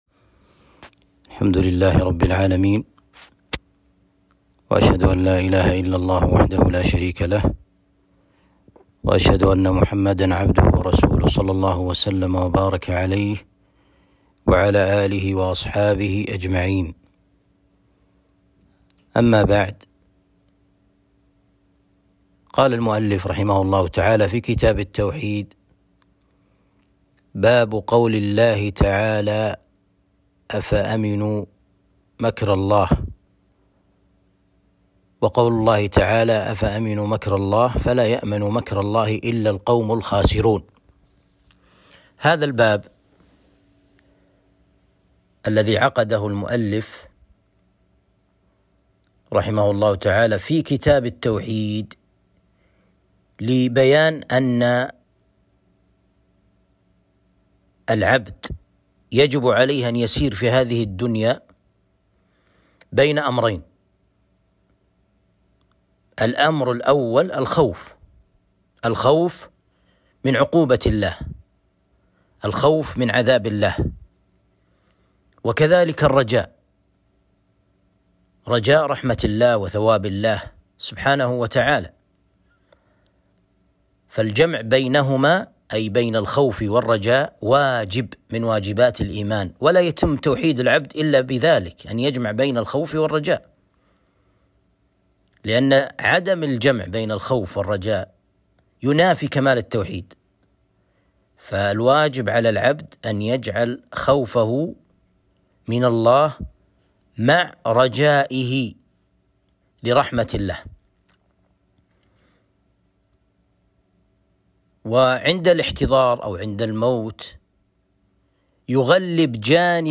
درس شرح كتاب التوحيد (34)